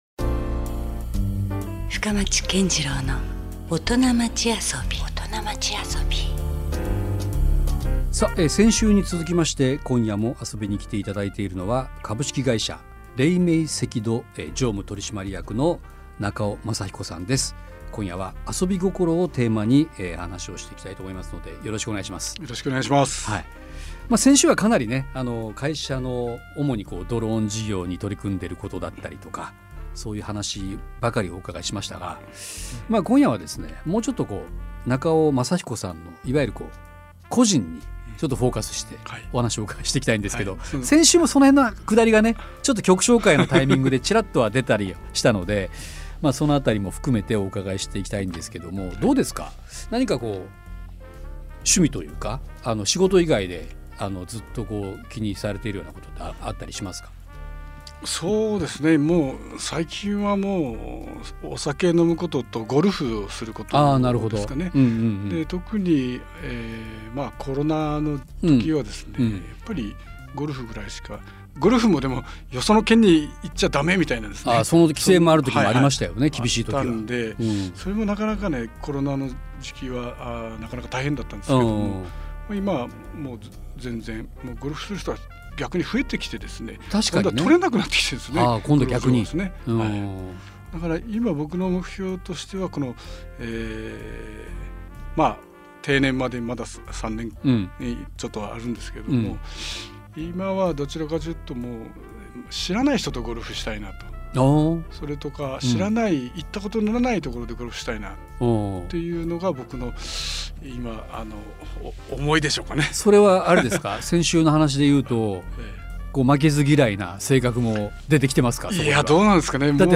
「革新的にシゴトをして、独創的にアソブ」そんなオトナをお迎えし、A面「シゴトへの姿勢」と、B面「アソビへのこだわり」についてお話を頂きます。各界でご活躍されているオトナのライフスタイルを通して、本当に”カッコイイオトナ”とは何なのかをフカボリしていきます。